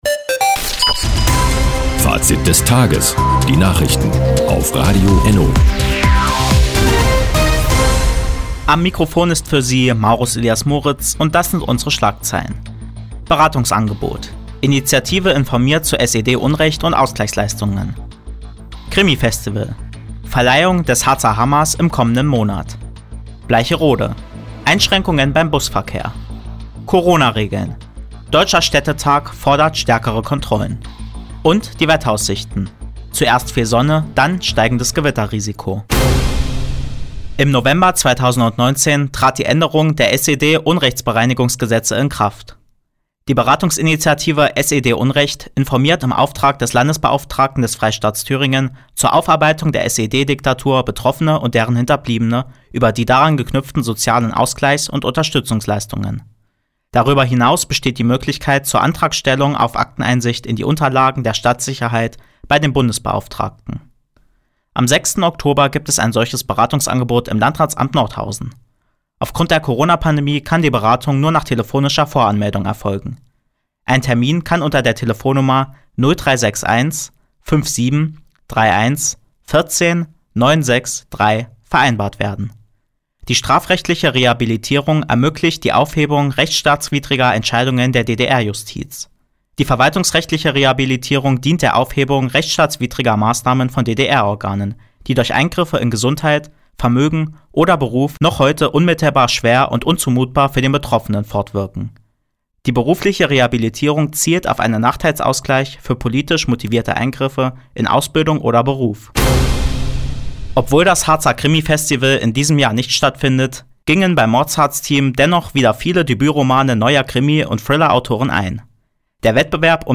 Fr, 16:20 Uhr 21.08.2020 Neues von Radio ENNO Fazit des Tages Anzeige Refinery (lang) Seit Jahren kooperieren die Nordthüringer Online-Zeitungen und das Nordhäuser Bürgerradio ENNO. Die tägliche Nachrichtensendung ist jetzt hier zu hören.